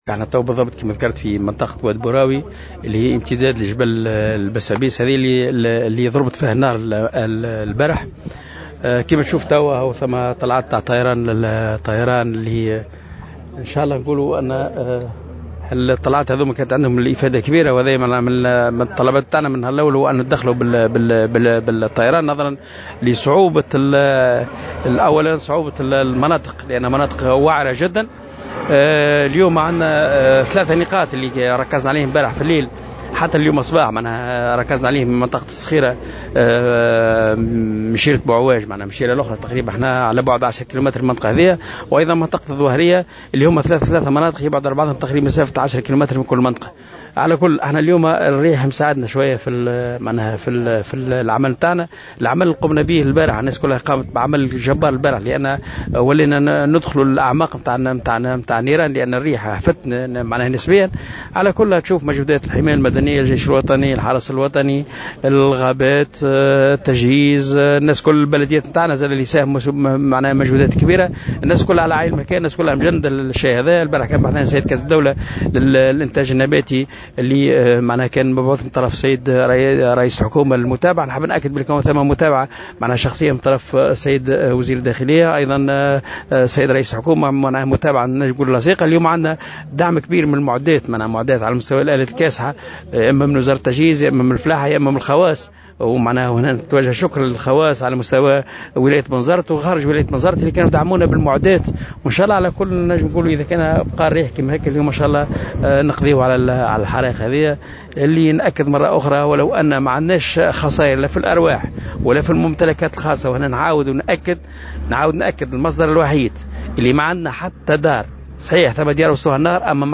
أكد والي بنزرت محمد قويدر في تصريح لمراسل "الجوهرة اف أم" اليوم، أن المجهودات متواصلة للسيطرة على حرائق في 3 مناطق بسجنان مازالت مندلعة، مشيرا إلى تدخل الطائرات لإخماد الحرائق نظرا لصعوبة التضاريس.